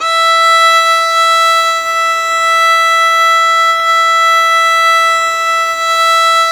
Index of /90_sSampleCDs/Roland - String Master Series/STR_Violin 4 nv/STR_Vln4 no vib
STR VLNBOW0F.wav